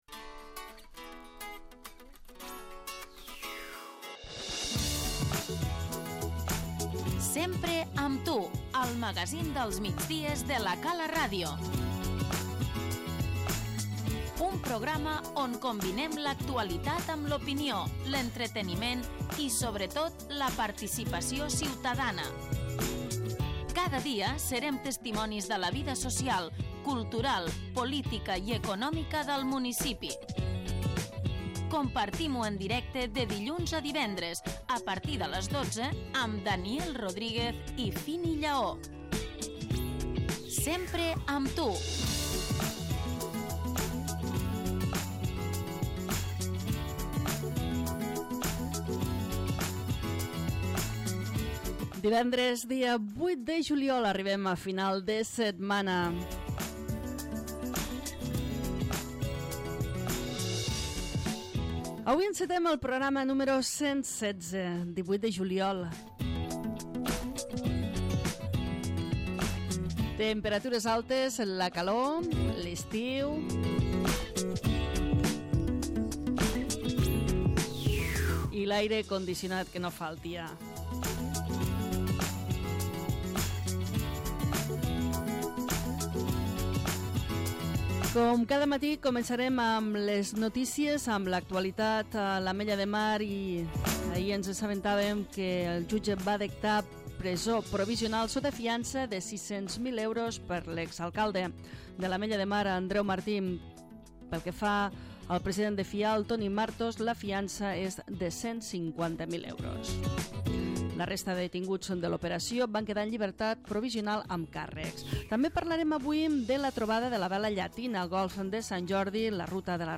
Acabem la setmana amb el Sempre amb tu núm. 1116. Avui, divendres dia 8 de juliol de 2016, en el magazín dels migdies de La Cala RTV, hem tractat les següents notícies i seccions: